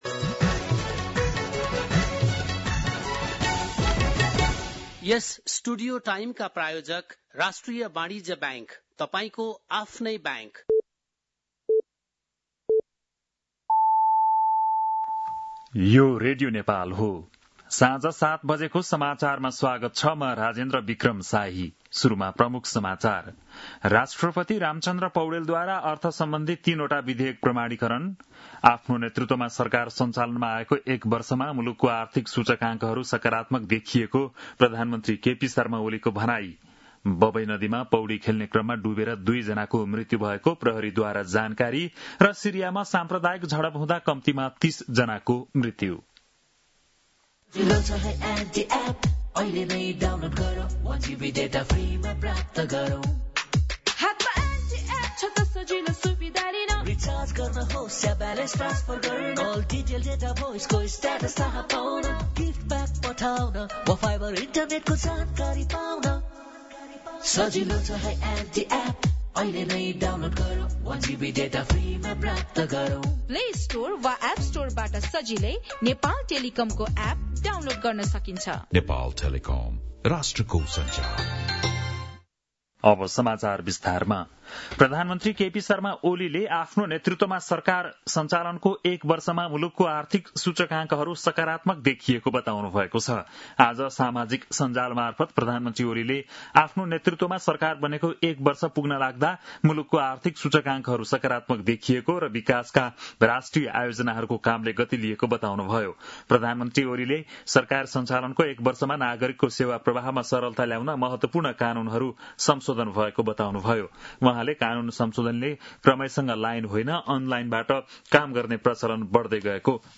बेलुकी ७ बजेको नेपाली समाचार : ३० असार , २०८२
7-pm-nepali-news-3-30.mp3